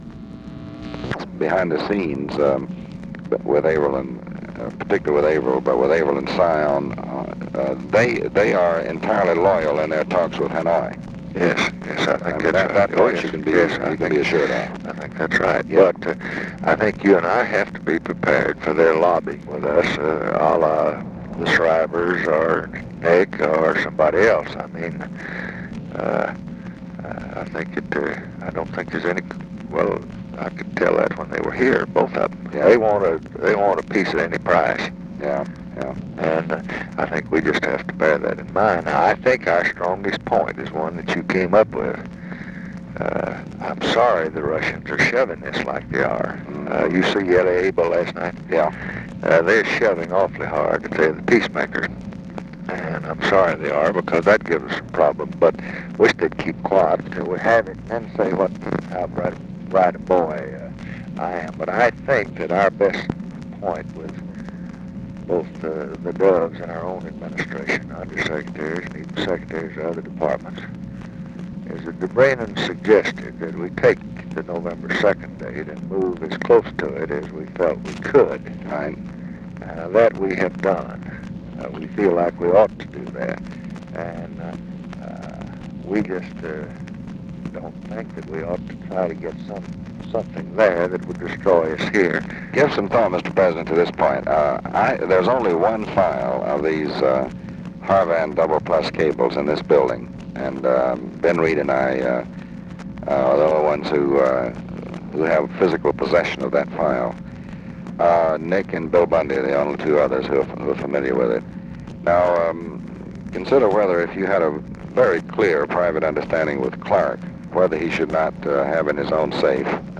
Conversation with DEAN RUSK, October 24, 1968
Secret White House Tapes